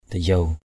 /d̪a-jau˨˩/ (d.) dây ống, dây ách = corde du joug. yoke rope. talei dayuw tl] dy~| dây ống = corde du joug.